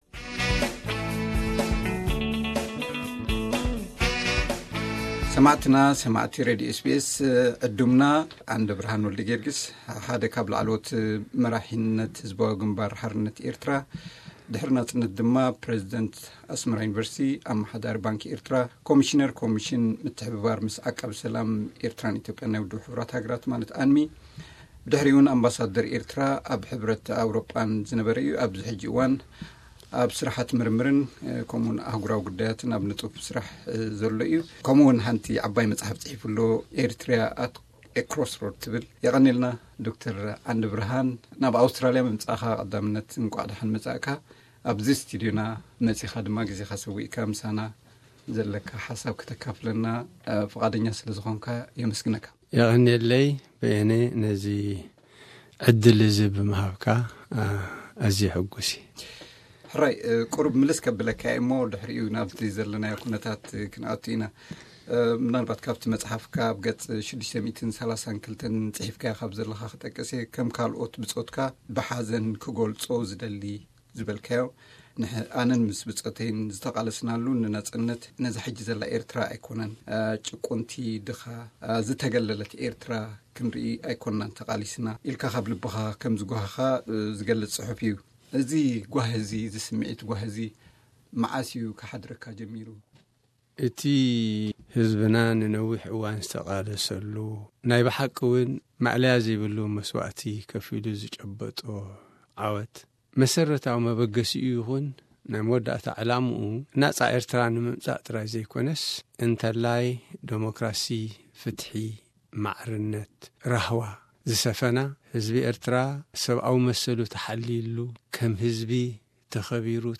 ኣብ ኣውስትራሊያ ኣብዝጸንሓሉ ምስ ሬድዮ ኤስ.ቢ.ኤስ ብዛዕባ ፖለቲካዊ ኩነታት ኤርትራ ዝገበሮ ቀዳማይ ክፋል ቃለ መሕትት እንሆ።